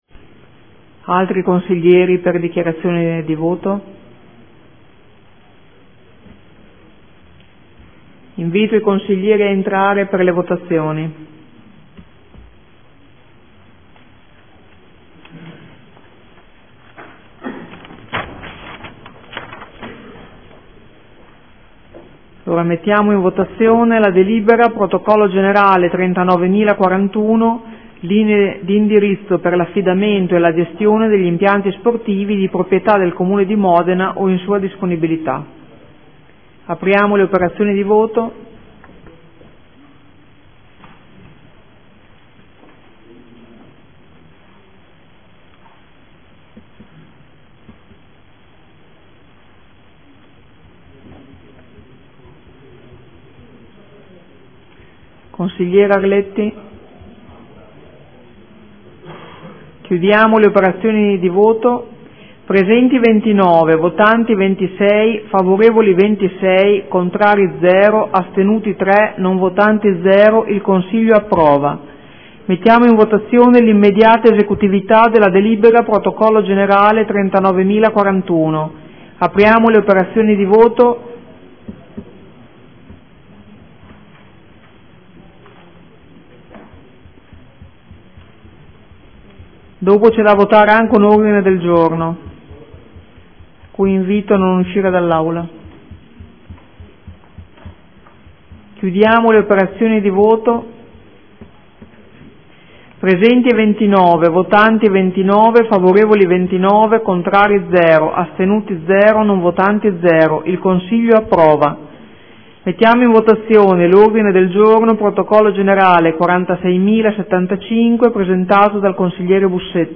Presidentessa